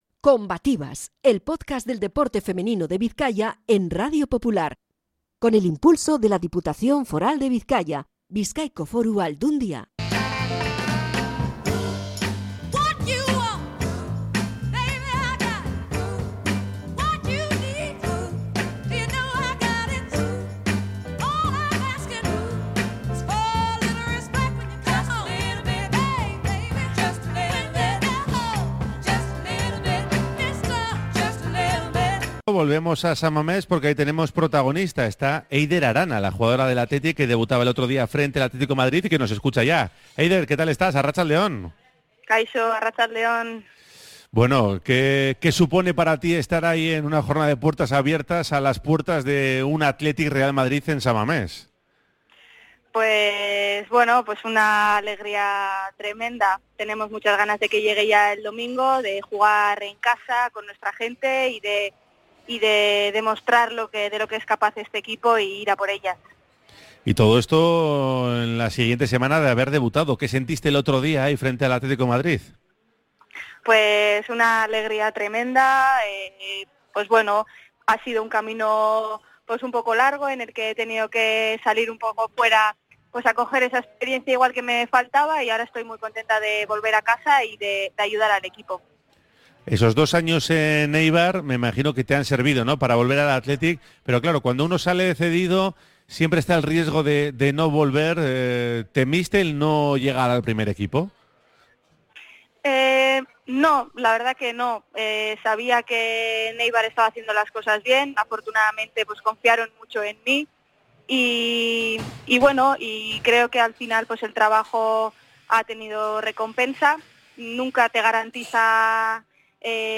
Entrevista con la jugadora del Athletic